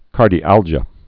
(kärdē-ăljə)